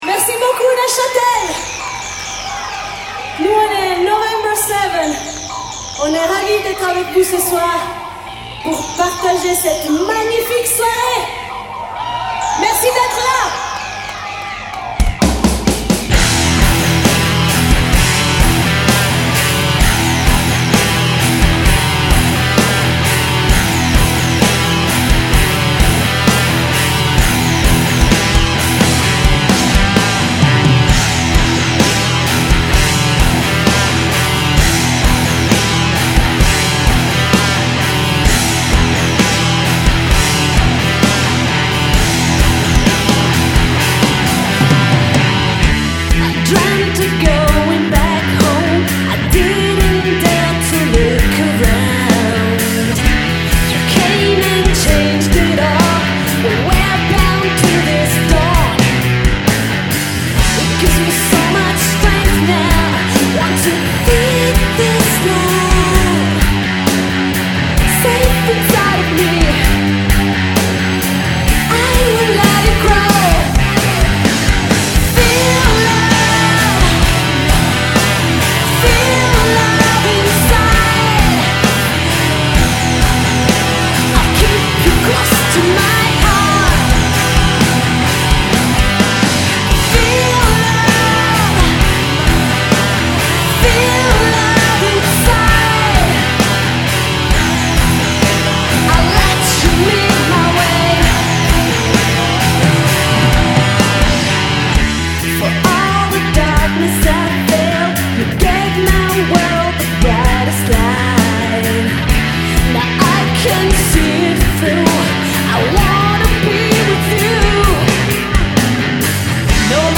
Vocals
Lead Guitars
Drums
Rythm Guitars
bass and samples
Recorded Live